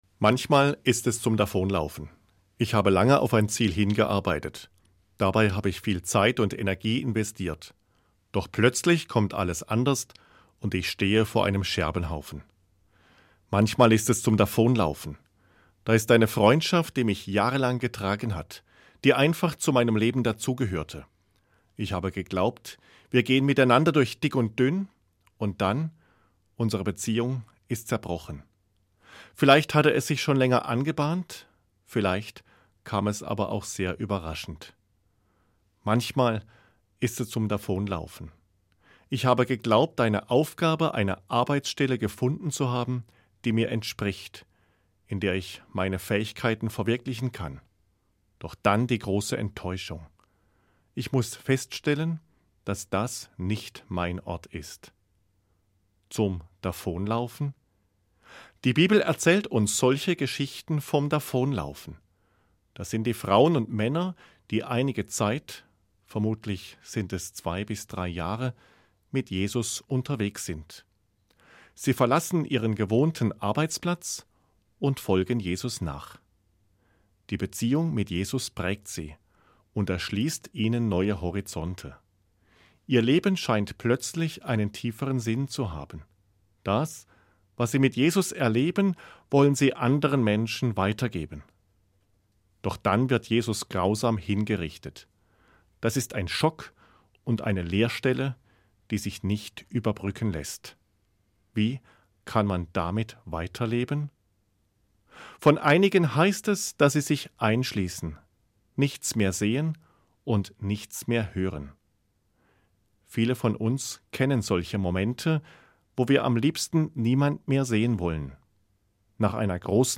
Eine Sendung von Dr. Michael Gerber, Bischof von Fulda